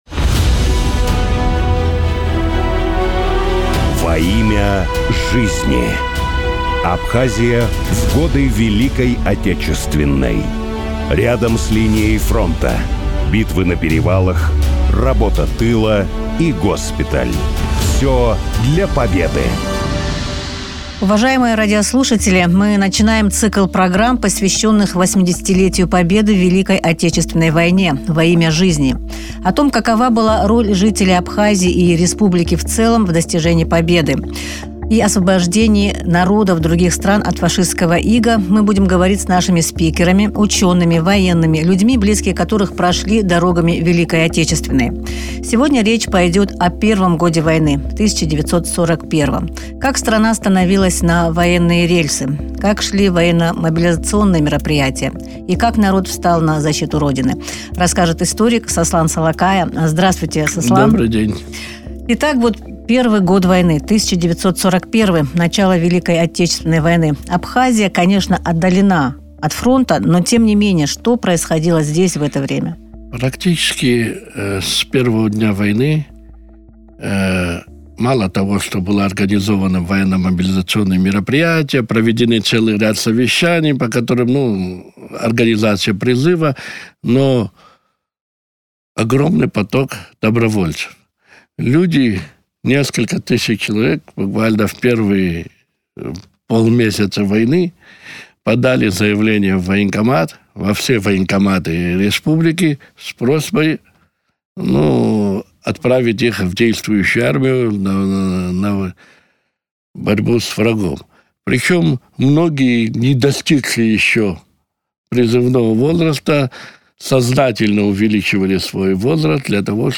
Абхазский историк